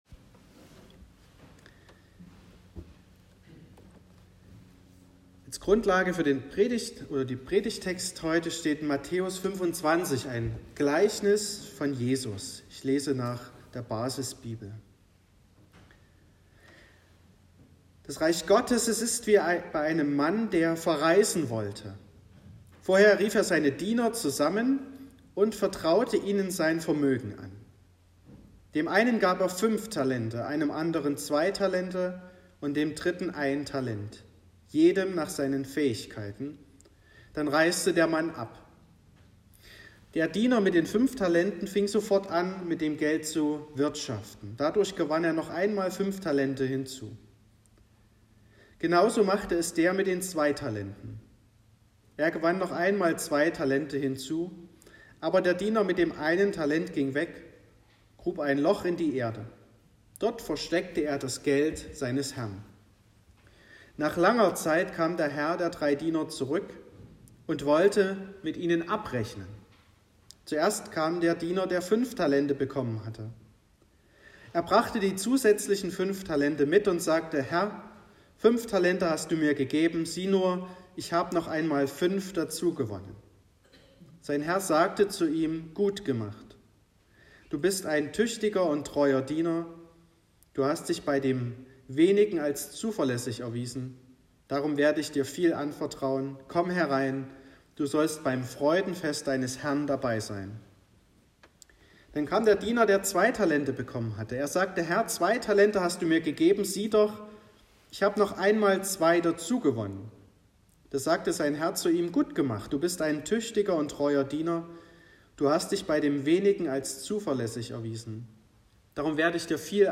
14.08.2022 – Gottesdienst
Predigt (Audio): 2022-08-14_Treu_dienen.m4a (9,0 MB)